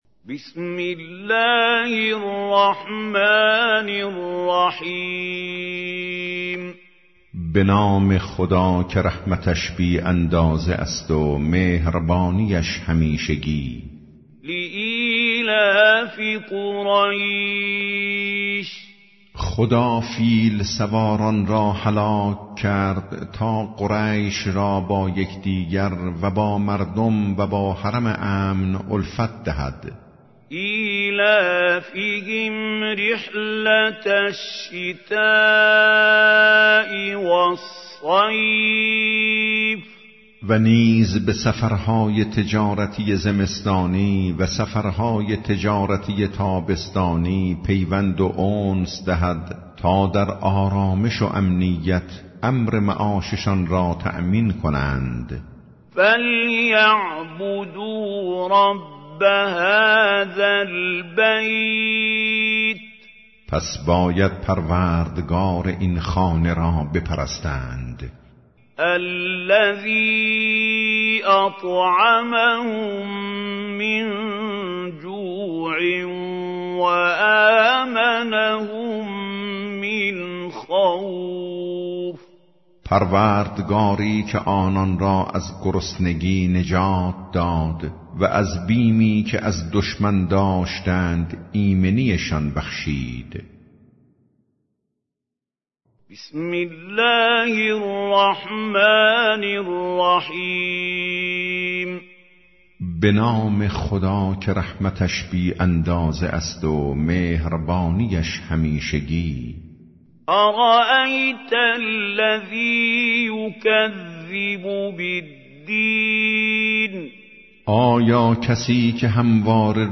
ترجمه گویای قرآن کریم - جزء ۳۰